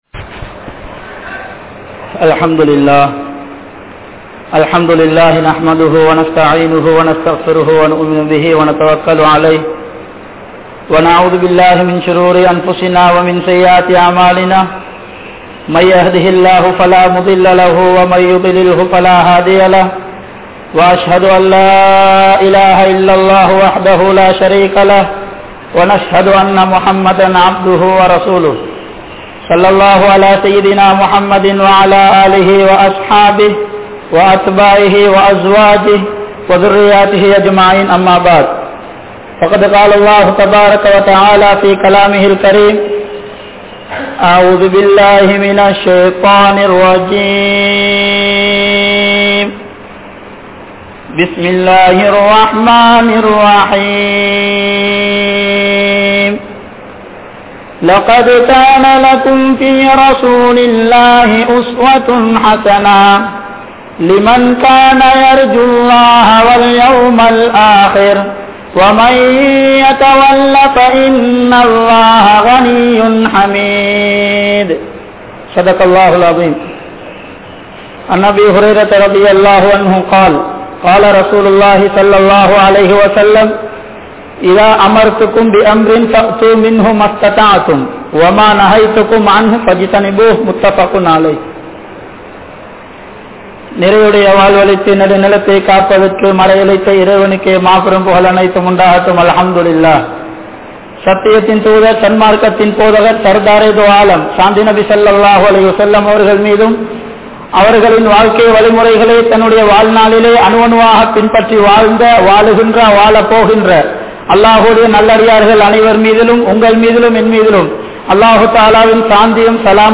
Nabi(SAW) Avarhalin Thiyaaham (நபி(ஸல்) அவர்களின் தியாகம்) | Audio Bayans | All Ceylon Muslim Youth Community | Addalaichenai
South Eastern University Jumua Masjith